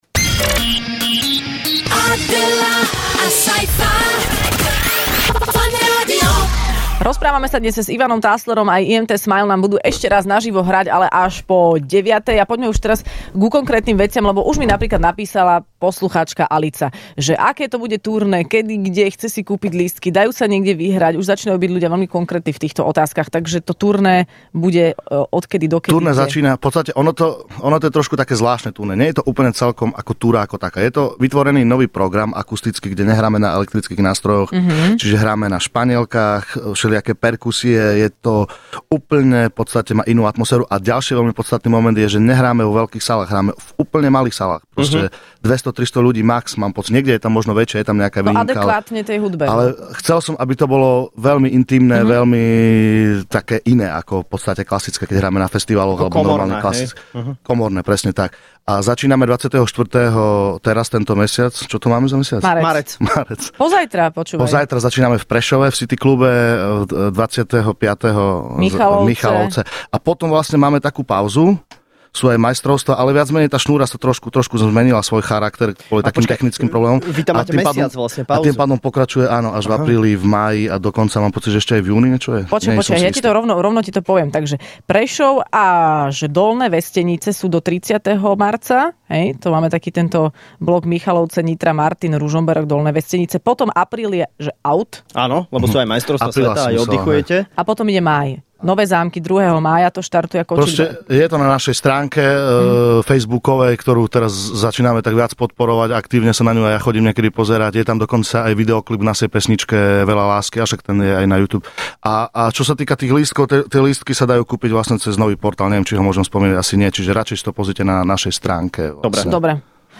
Hosťom v Rannej šou bola dnes skupina IMT Smile